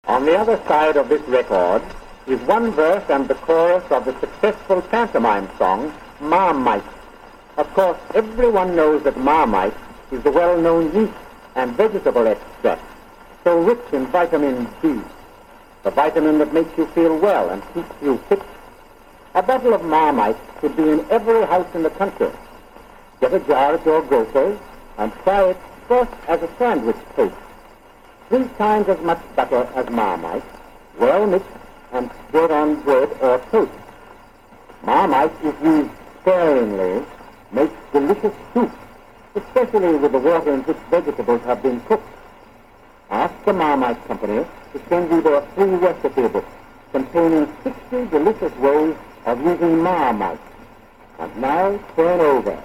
He was trying to find a copy of the Ma-Might song from the 1920’s. It was an early advert for Marmite and only came on small gramophone records, this is pre radio and apart from live advertising in theatres, this was the only other way to have vocal advertising.
The quality wasn’t the best, but the fact that it survived the last 90 to 95 years, when these are so delicate, is a miracle.